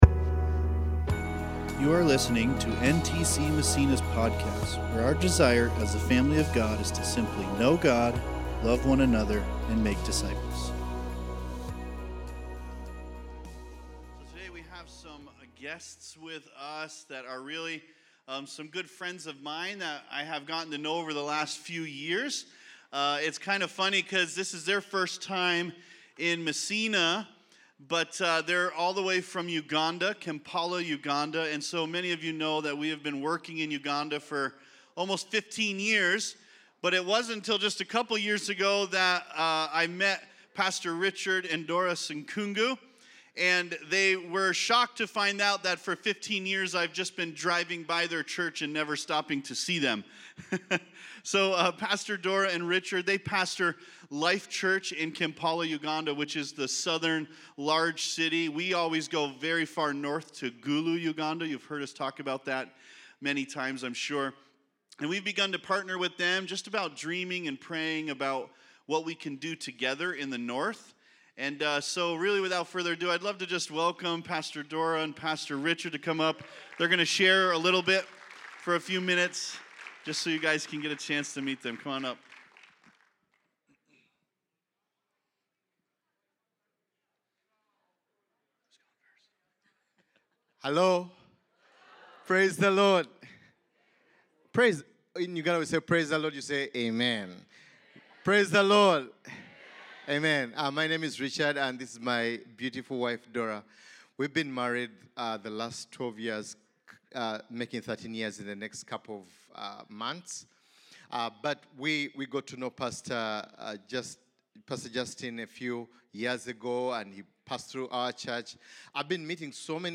2024 Growing W.1 Preacher